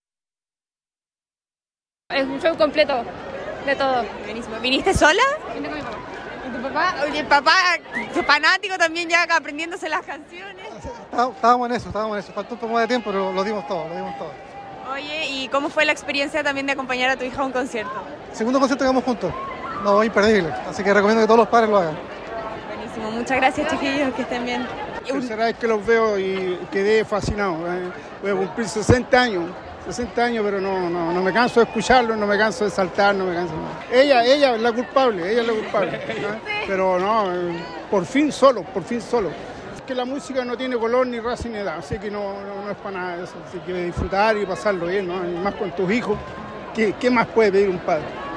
Incluso los más grandes quedaron encantados con este show. Un papá que compaño a su hijo dijo a La Radio.
cu-top-papas.mp3